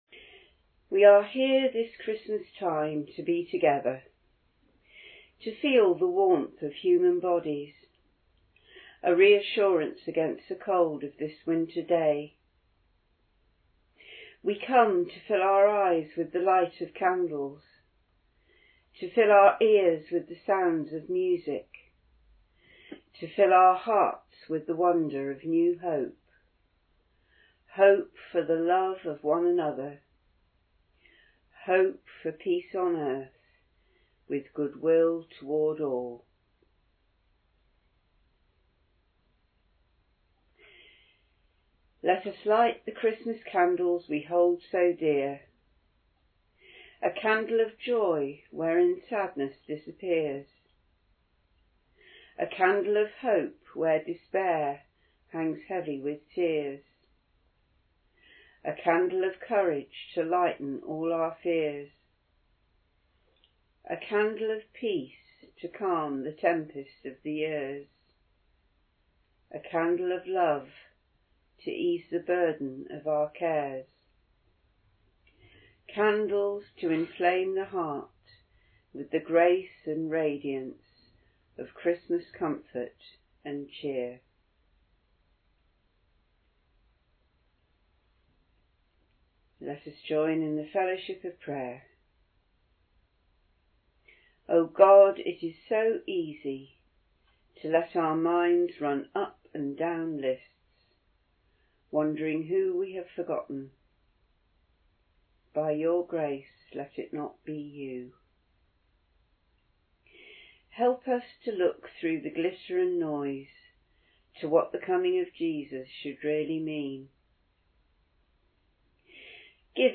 9-lessons-carols-trimmed.mp3